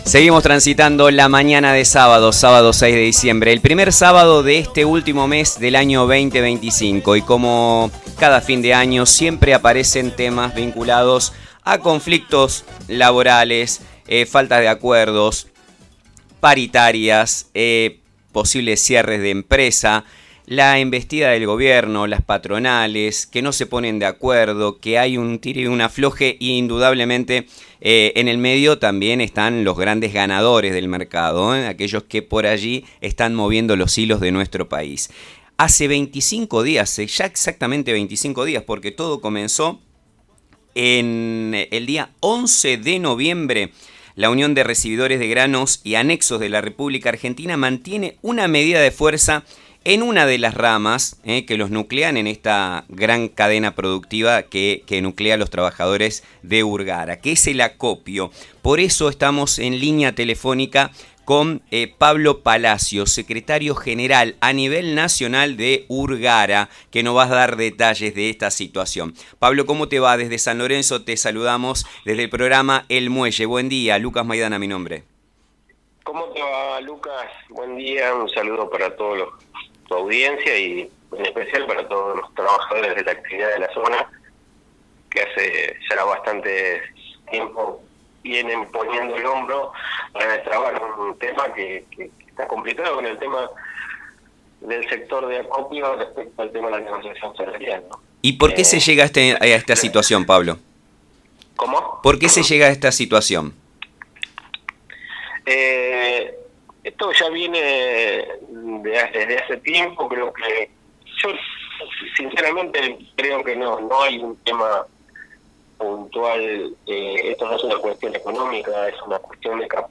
En una entrevista concedida al programa El Muelle de Nueva Estrella Medios